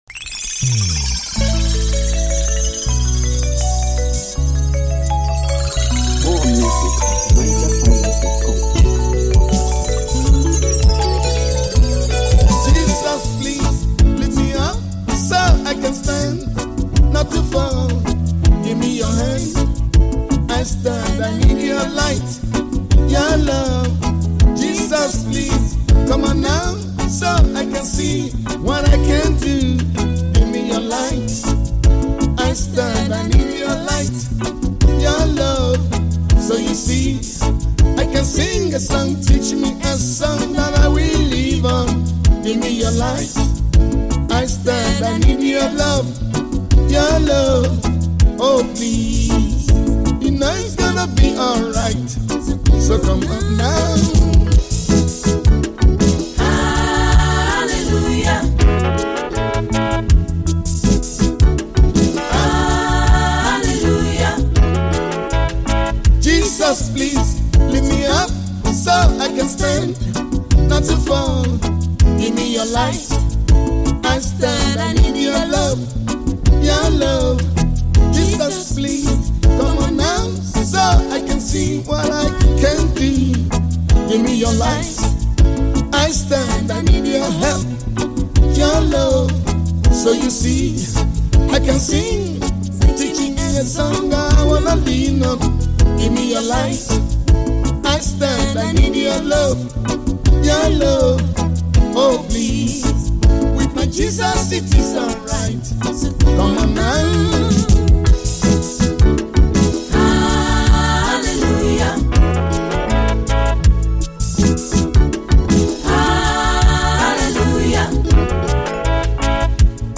Home » Highlife